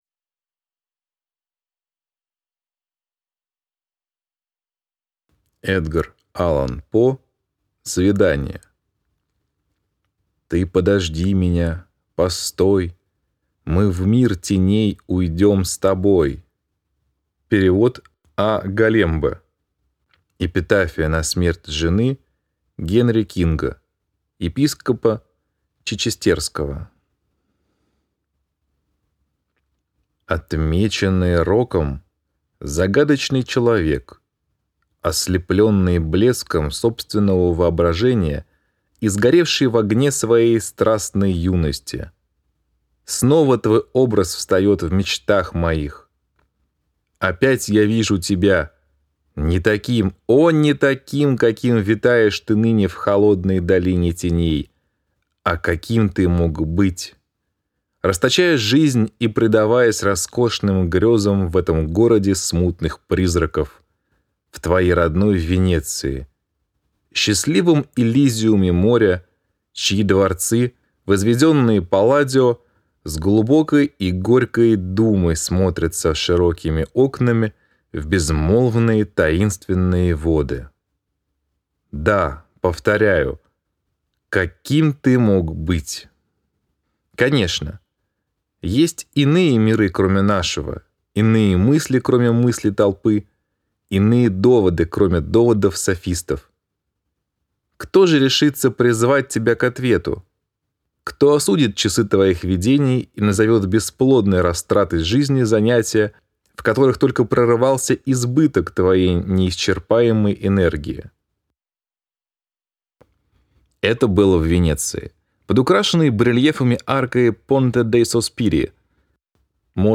Аудиокнига Свидание | Библиотека аудиокниг